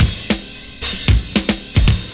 112CYMB03.wav